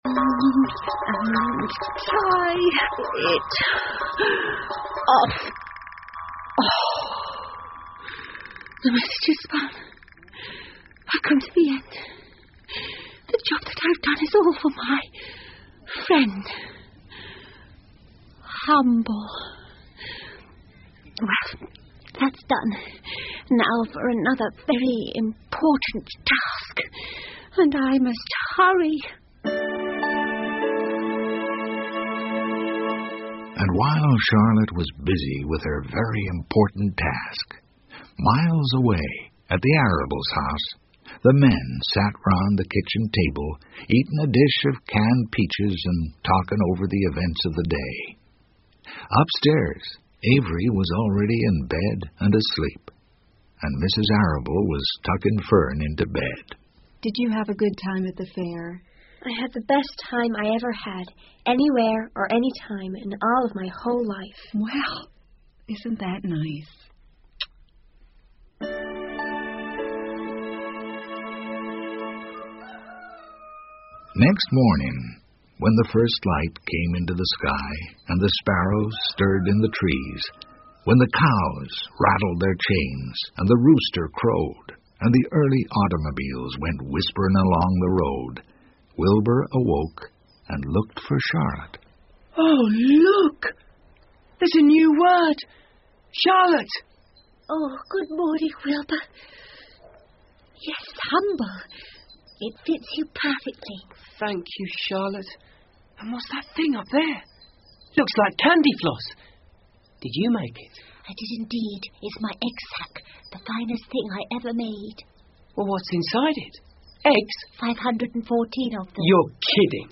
夏洛的网 Charlottes Web 儿童广播剧 14 听力文件下载—在线英语听力室